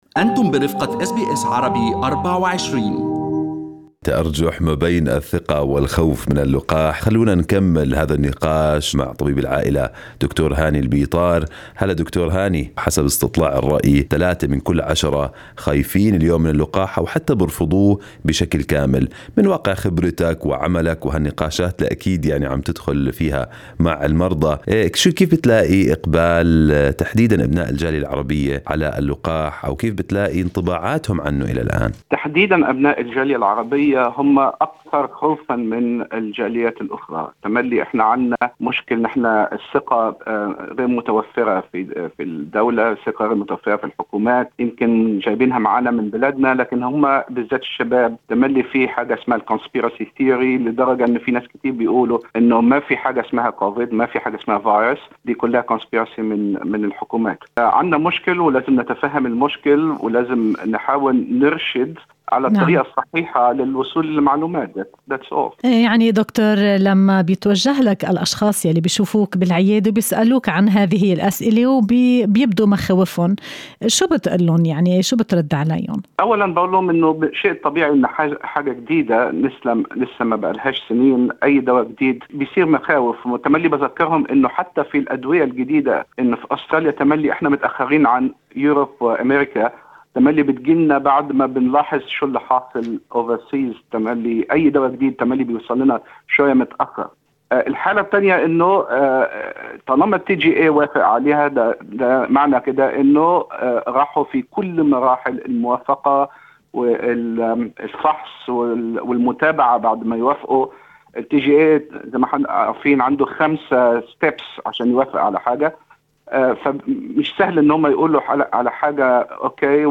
طبيب عائلة: لكل دواء أو لقاح أعراض جانبية ولكن احتمالية وقوعها نادرة